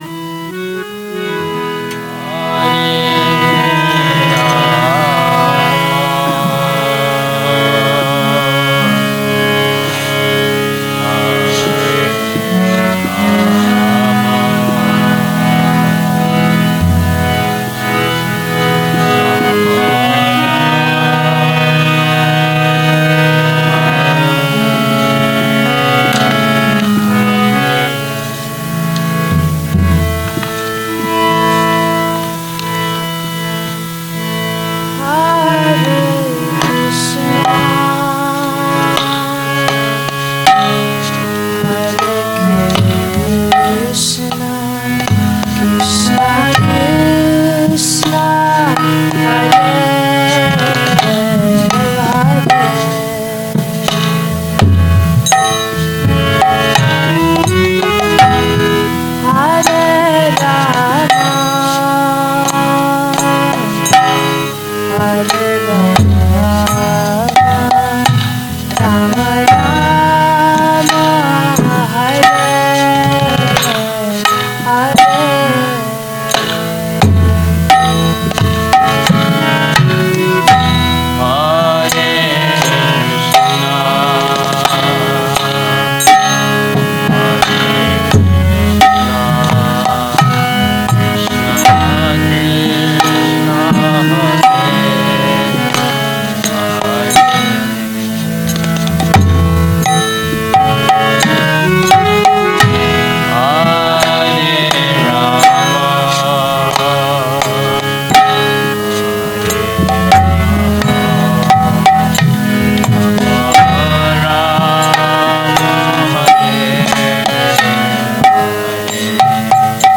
A lecture
Govardhana Retreat Center